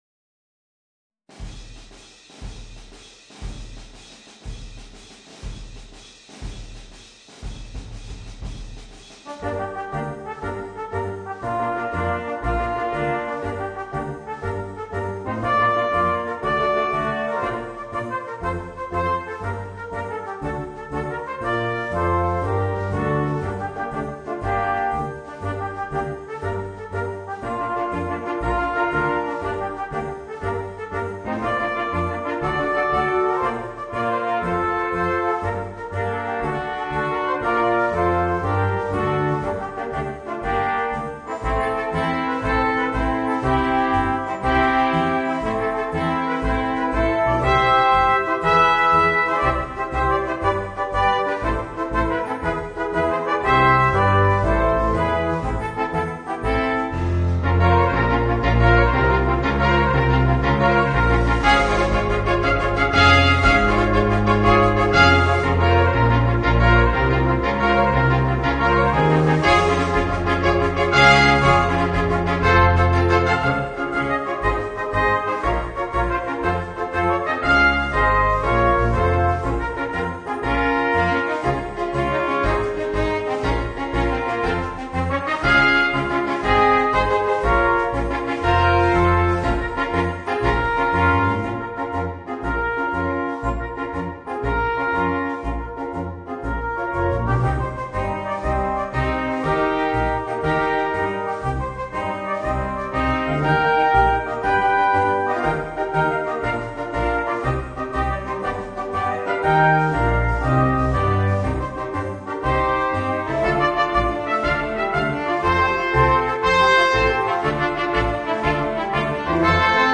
Voicing: 2 Trumpets, 2 Trombones and Drums